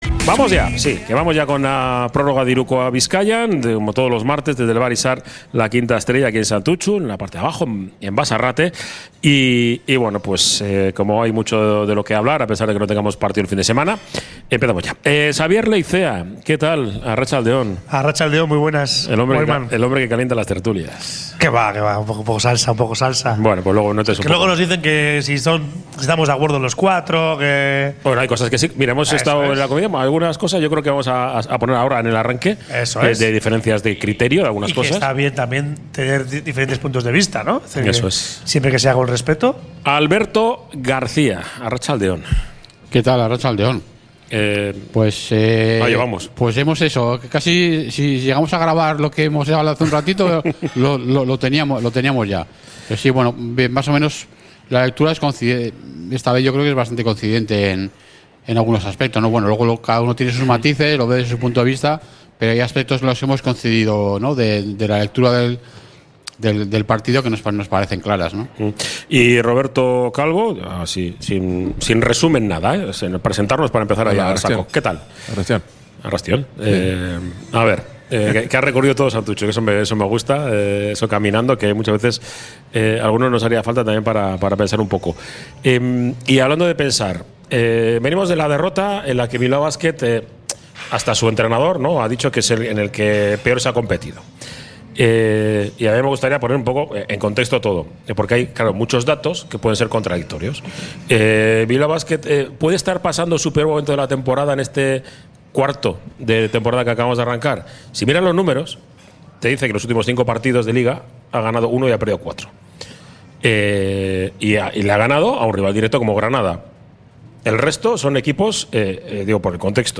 Desde el Bar Izar la Quinta Estrella de Santutxu